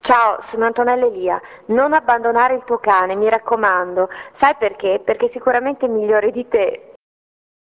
ASCOLTA GLI SPOT DI ANTONELLA ELIA